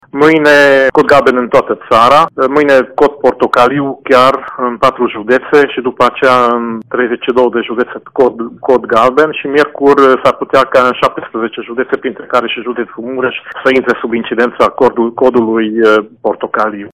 Codul galben de caniculă va fi extins mâine în toată țara, și există posibilitatea ca miercuri câteva județe, printre care și Mureșul, să treacă sub cod portocaliu, a arătat Nagy Zsigmond subprefect: